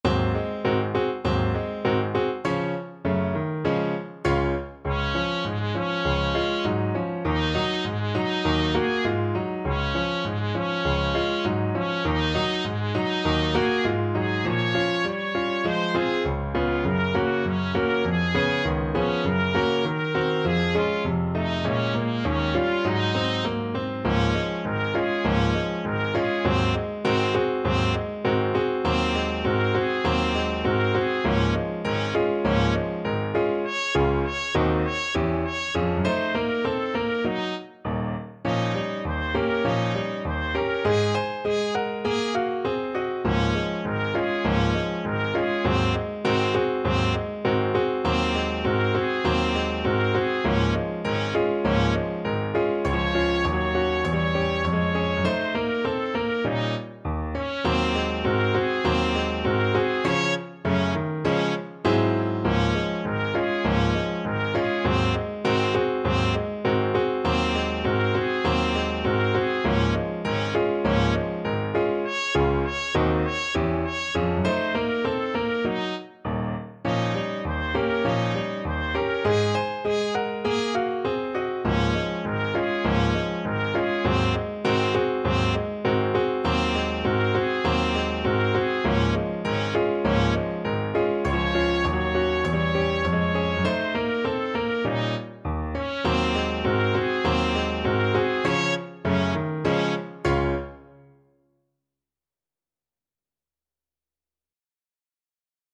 Moderato =c.100